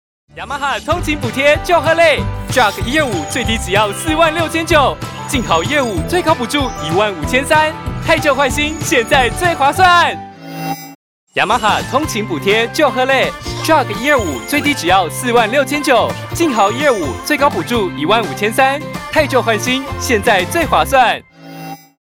國語配音 男性配音員